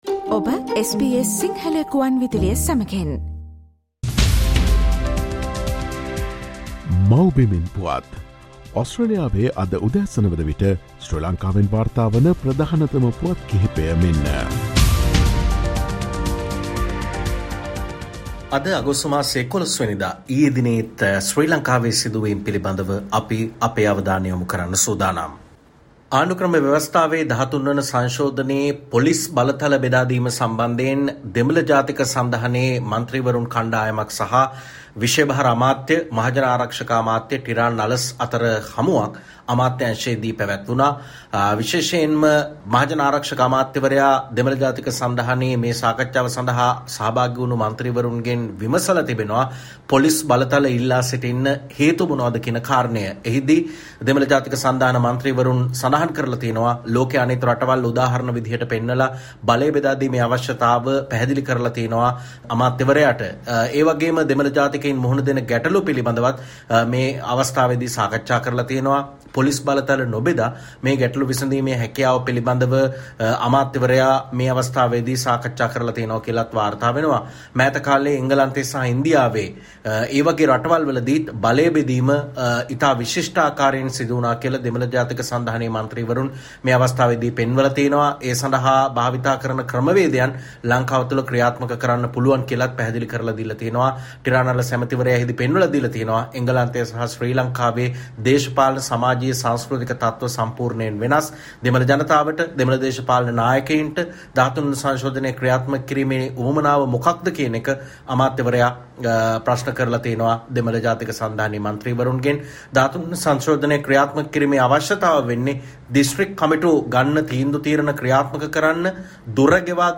Featuring the latest news reported from Sri Lanka - "Mawbimen Puwath"